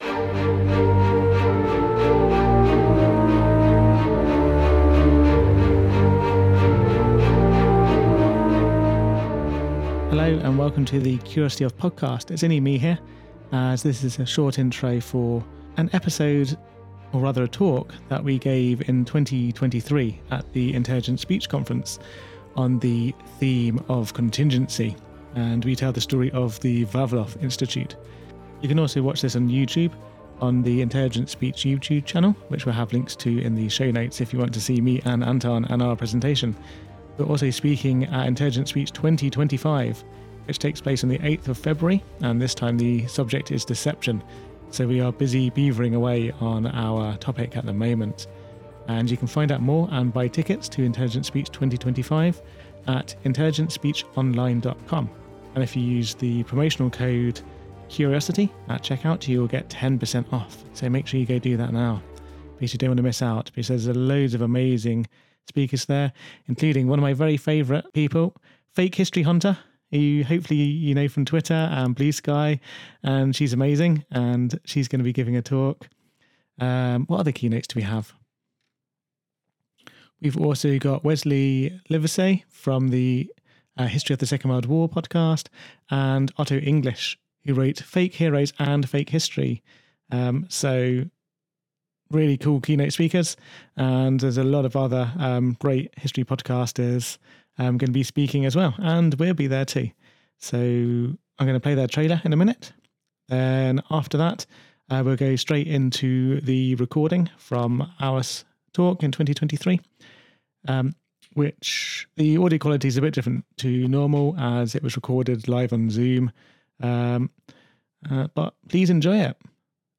A podcast about history, science, tales and everything in between. A father and son discuss things they are curious about from science and history to monsters and games.